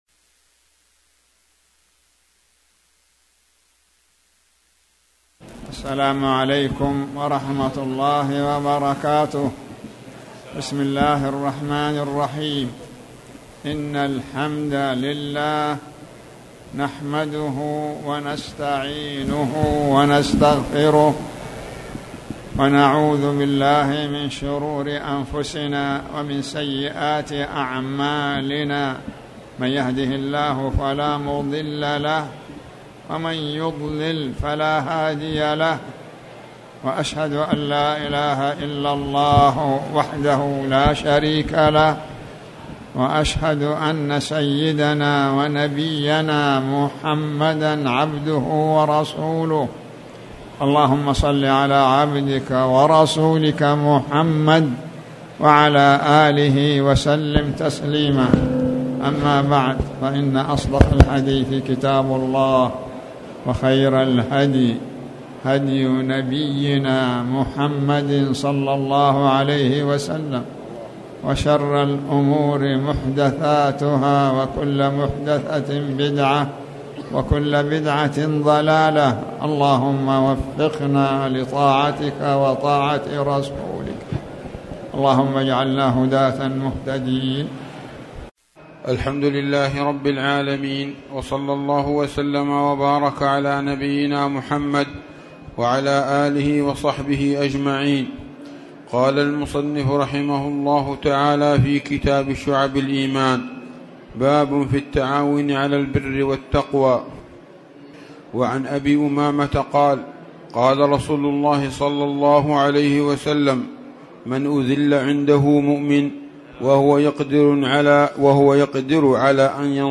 تاريخ النشر ٢ ذو القعدة ١٤٣٨ هـ المكان: المسجد الحرام الشيخ